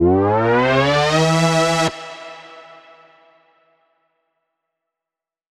Index of /musicradar/future-rave-samples/Siren-Horn Type Hits/Ramp Up
FR_SirHornC[up]-E.wav